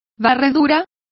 Complete with pronunciation of the translation of sweepings.